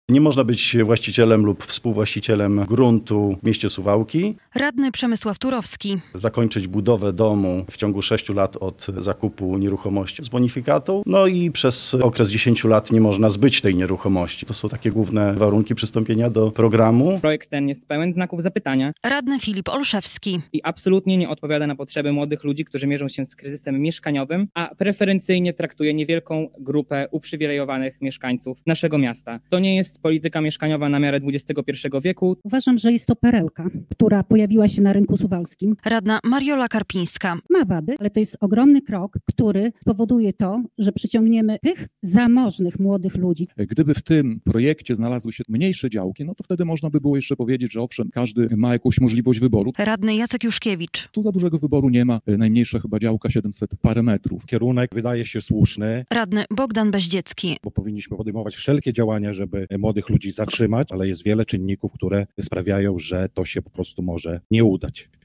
Sesja Rady Miejskiej Suwałk, 27.11.2024, fot.
Działki z bonifikatą - relacja